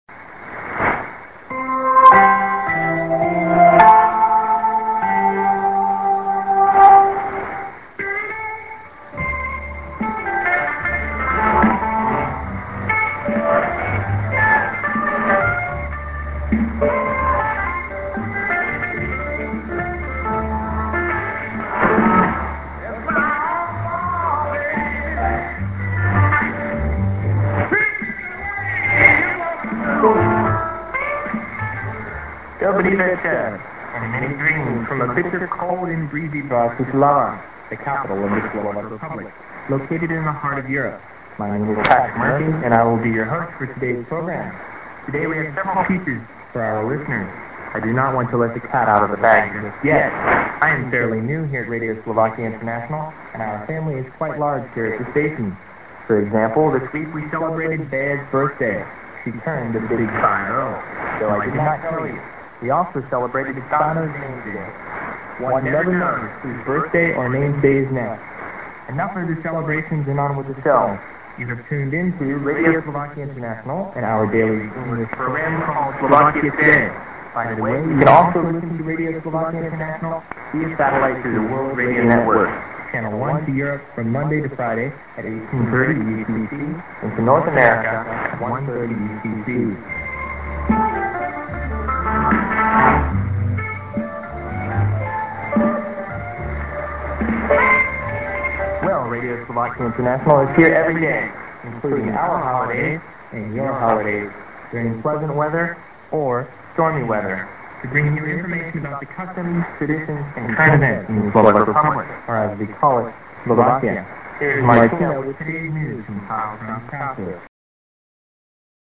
b:　IS->music->ID+SKJ(man)->